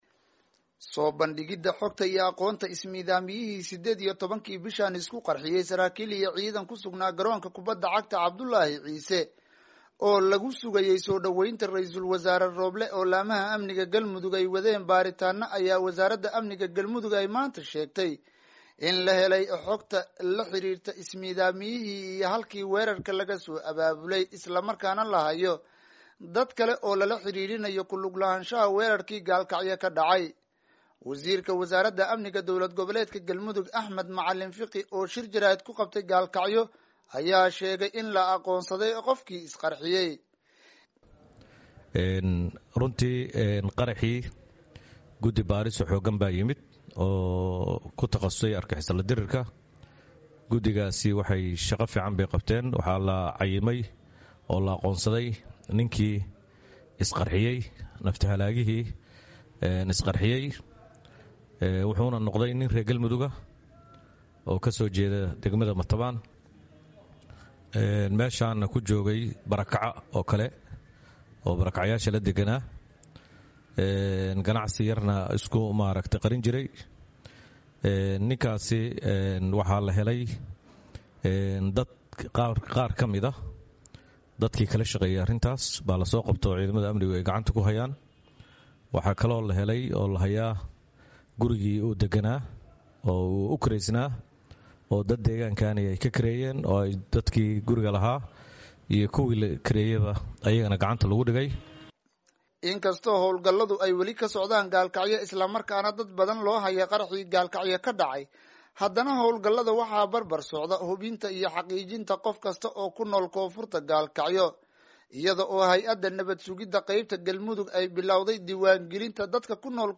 Warbixintan waxaa soo diray weriyahayaga